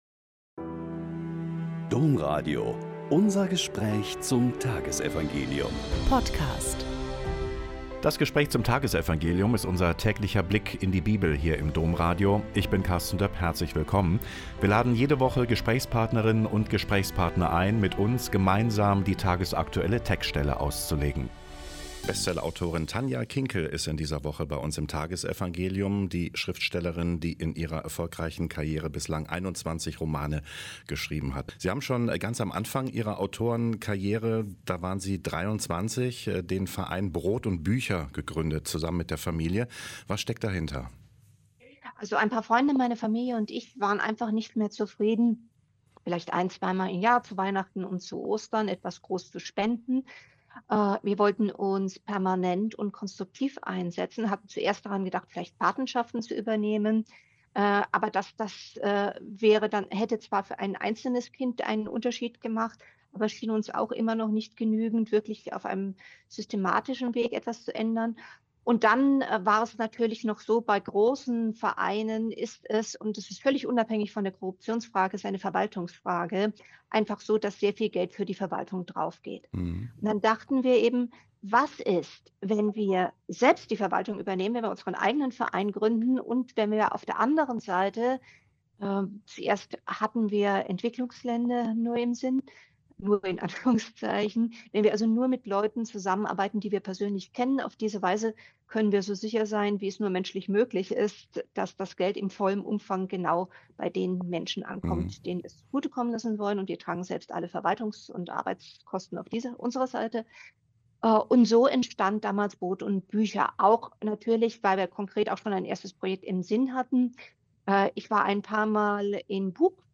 Joh 1,29-34 - Gespräch mit Tanja Kinkel